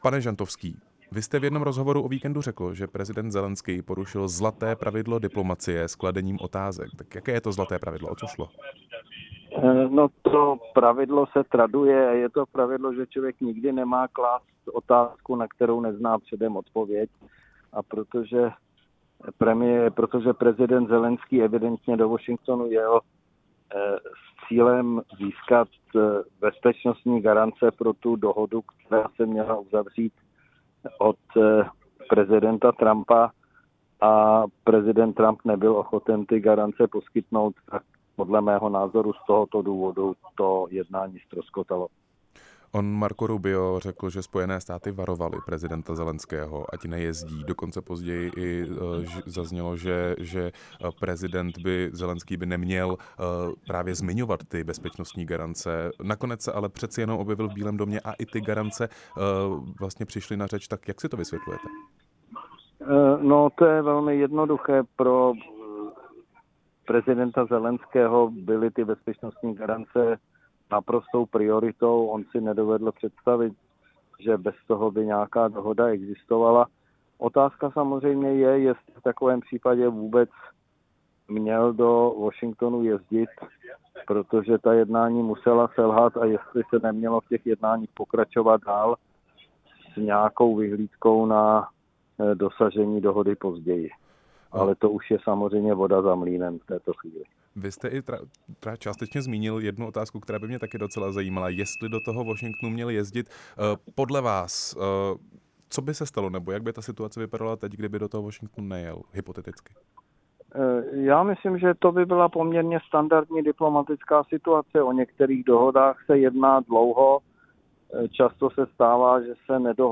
Rozhovor s diplomatem Michaelem Žantovským